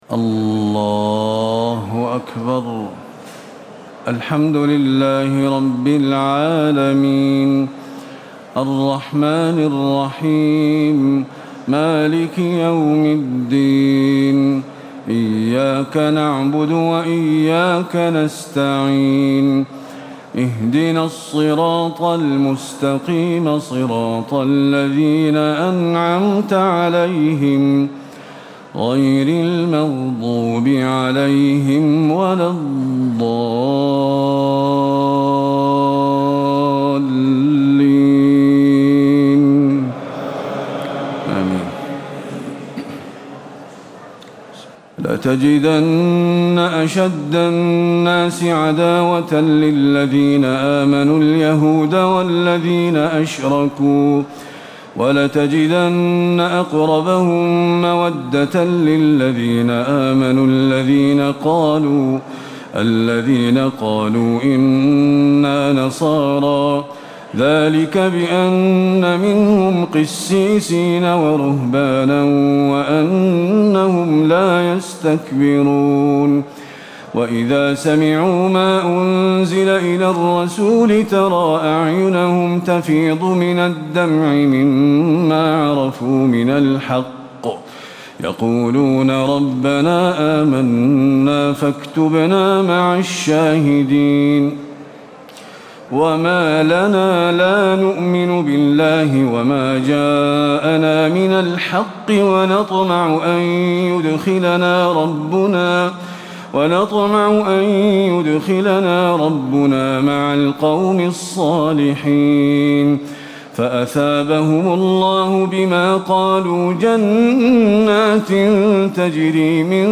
تهجد ليلة 27 رمضان 1437هـ من سورتي المائدة (82-120) و الأنعام (1-58) Tahajjud 27 st night Ramadan 1437H from Surah AlMa'idah and Al-An’aam > تراويح الحرم النبوي عام 1437 🕌 > التراويح - تلاوات الحرمين